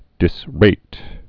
(dĭs-rāt)